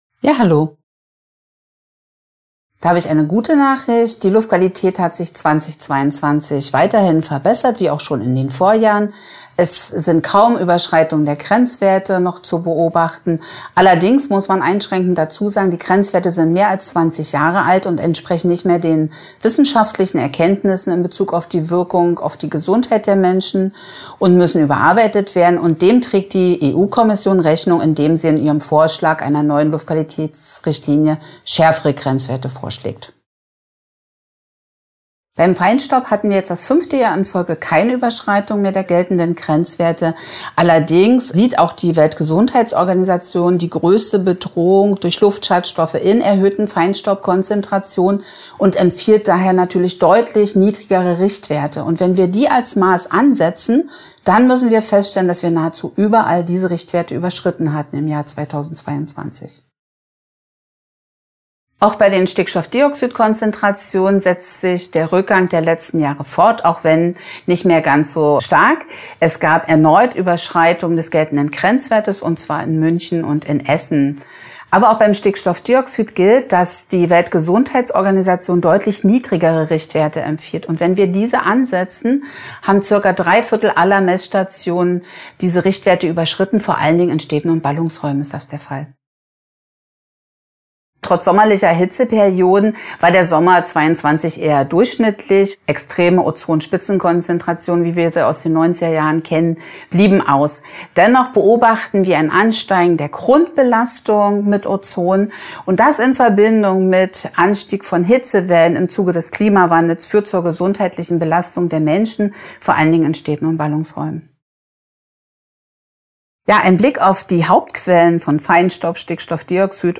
Interview: 2:29 Minuten